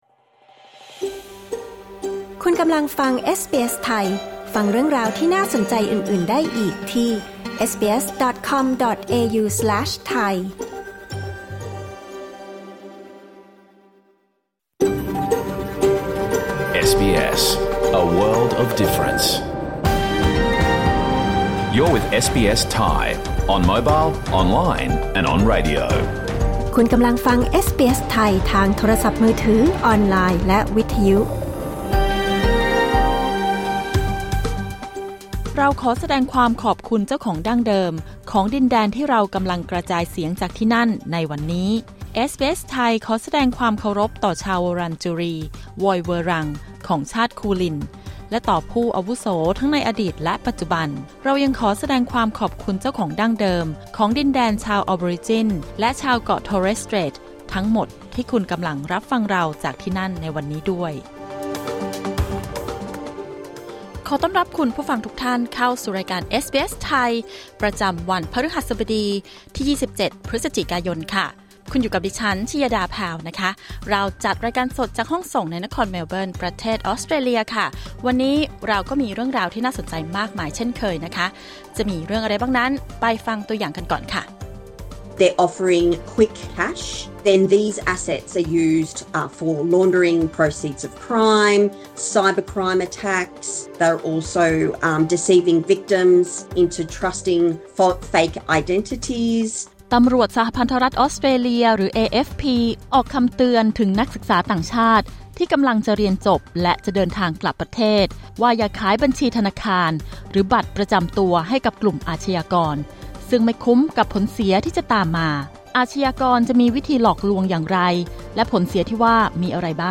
รายการสด 27 พฤศจิกายน 2568